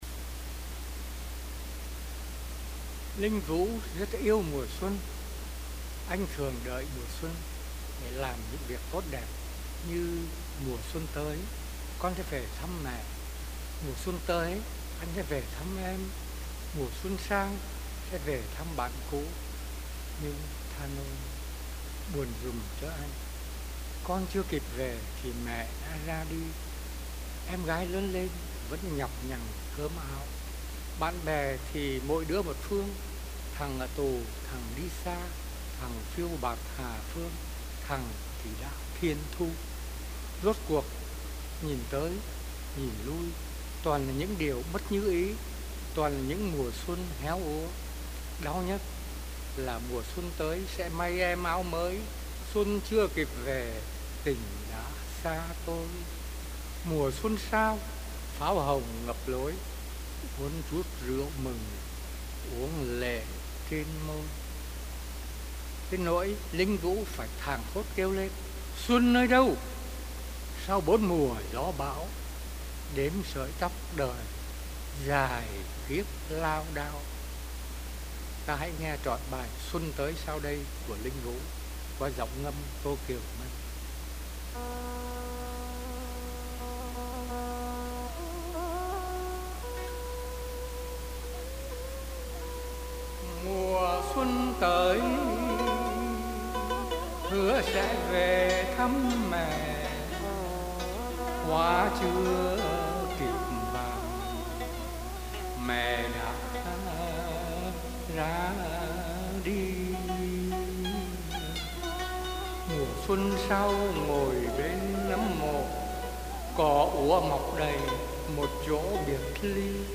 Phần 3: Ngâm thơ Thi tập phía sau nỗi buồn